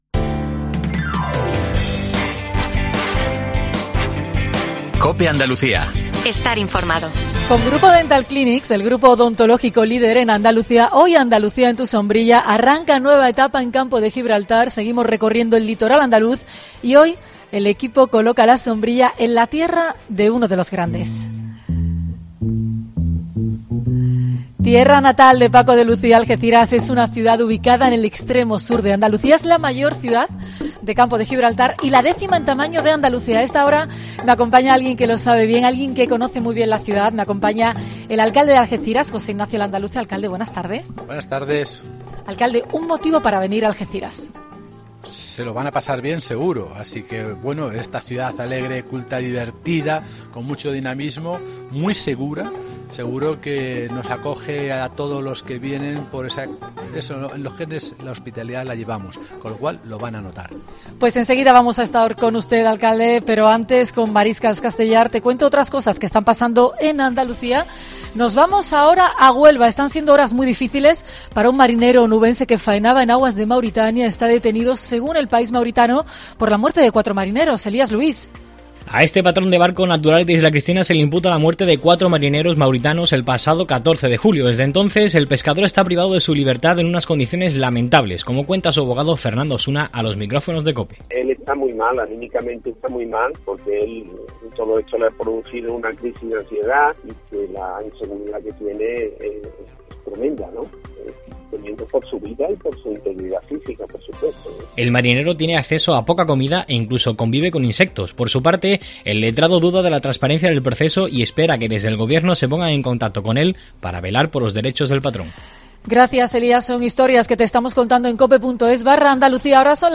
Nos lo cuenta su alcalde en esta entrevista que puedes escuchar aquí.